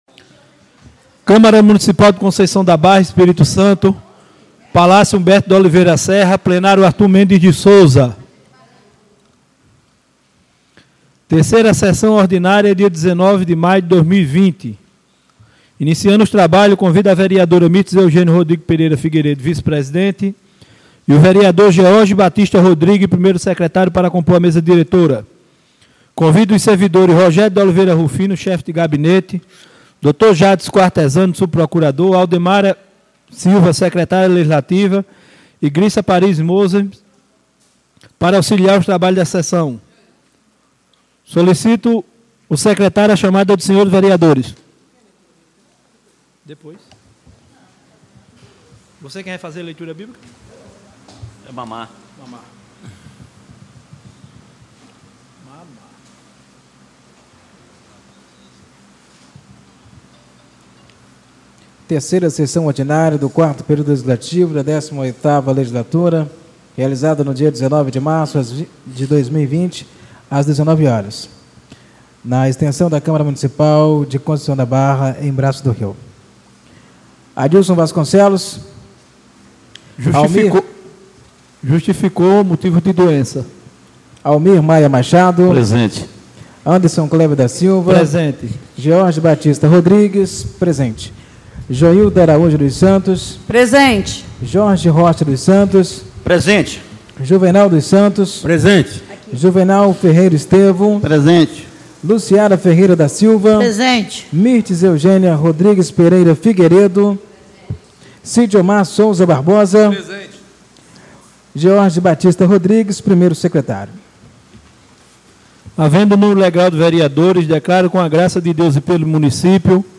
3ª Sessão Ordinária do dia 19 de março de 2020